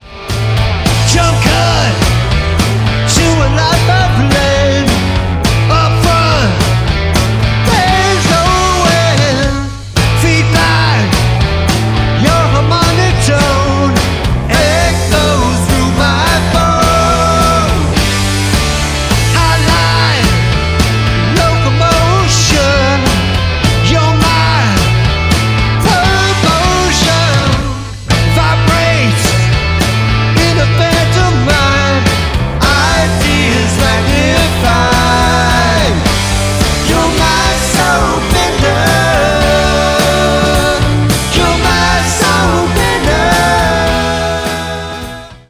live in-the-studio